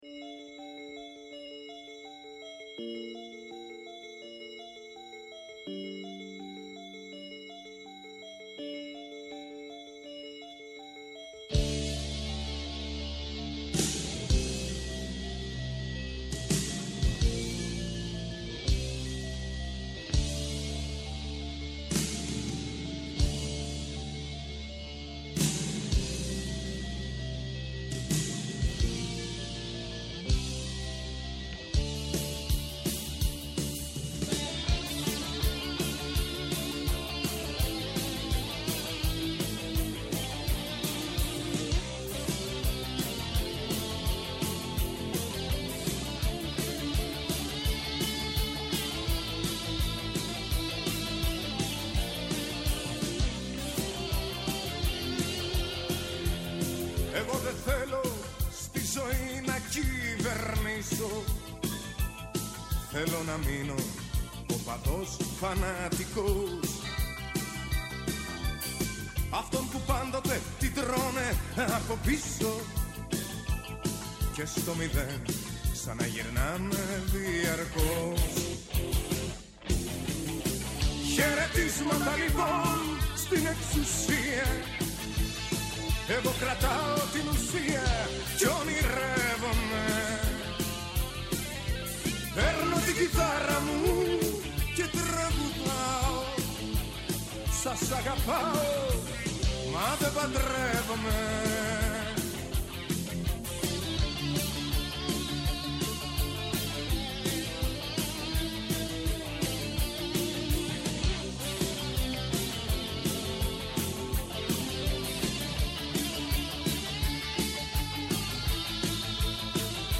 Καλεσμένοι τηλεφωνικά στην σημερινή εκπομπή: